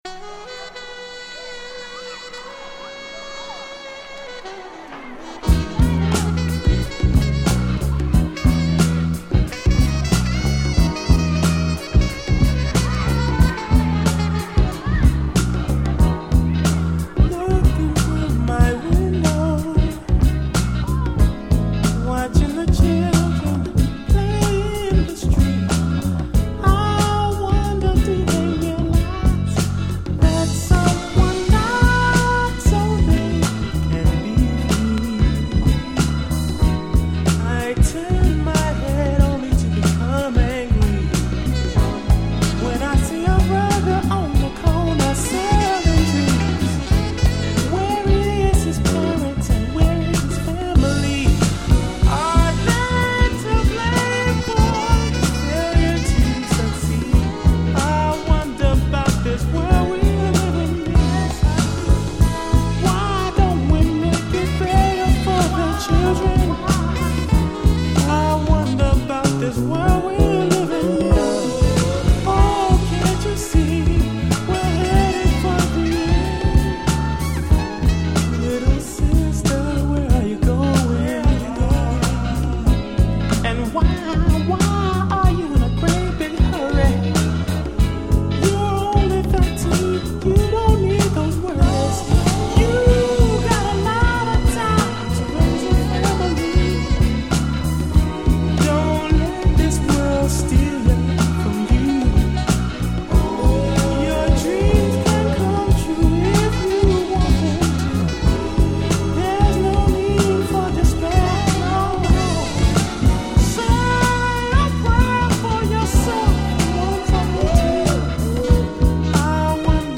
【Media】Vinyl LP
90' Nice Soul/R&B LP !!